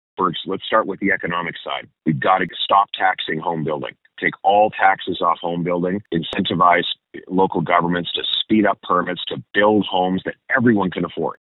In an interview with Quinte News, Poilievre spoke about the transition from Prime Minister Justin Trudeau to Mark Carney.